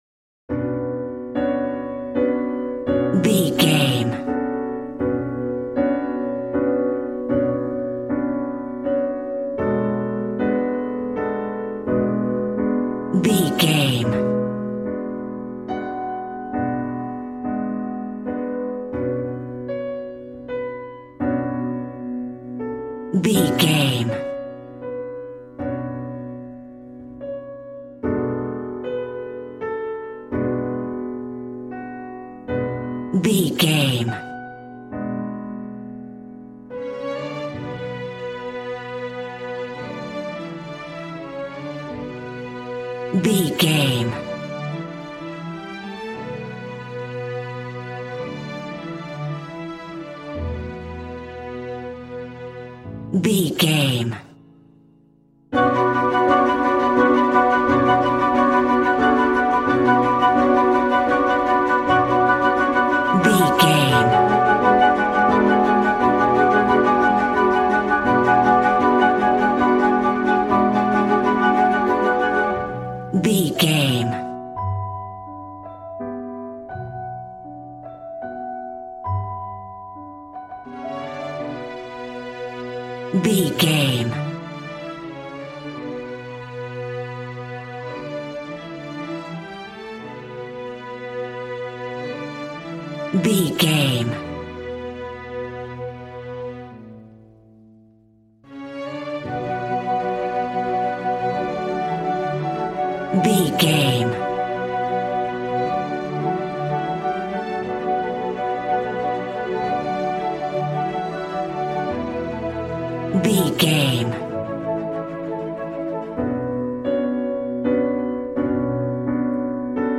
Regal and romantic, a classy piece of classical music.
Aeolian/Minor
regal
strings
violin
brass